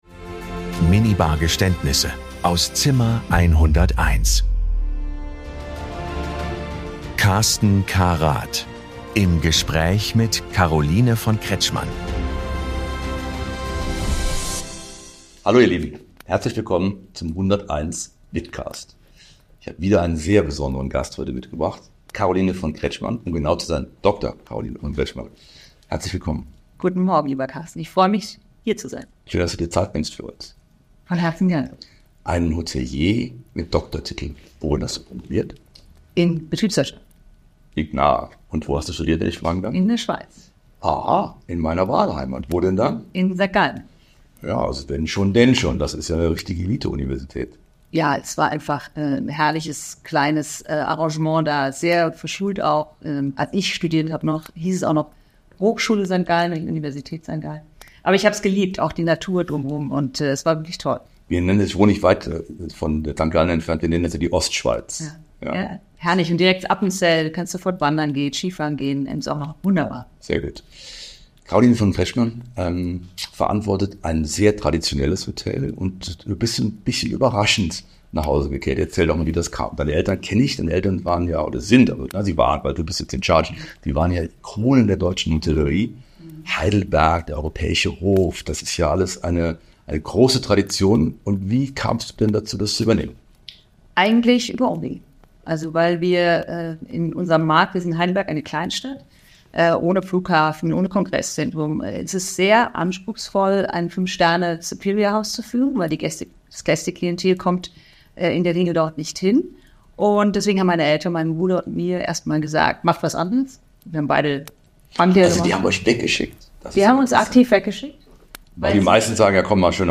Es wird reflektiert, offen gesprochen und manchmal auch grundlegend hinterfragt. Hier wird Hospitality persönlich.